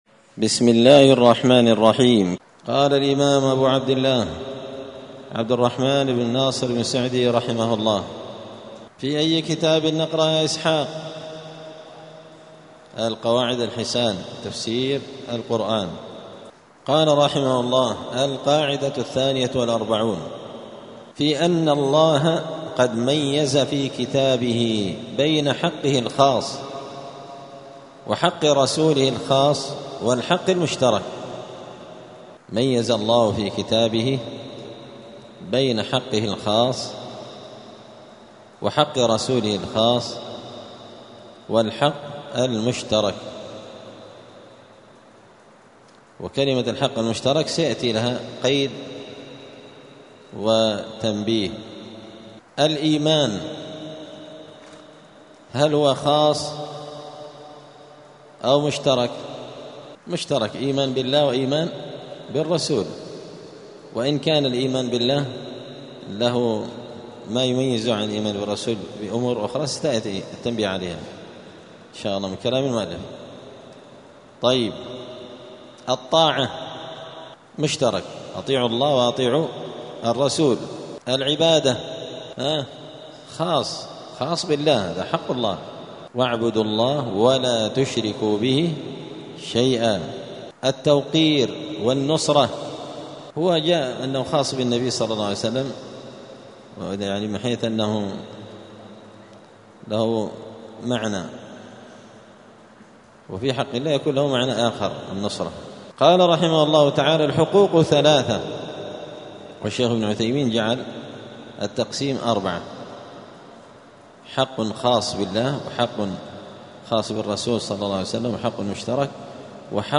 دار الحديث السلفية بمسجد الفرقان قشن المهرة اليمن
56الدرس-السادس-والخمسون-من-كتاب-القواعد-الحسان.mp3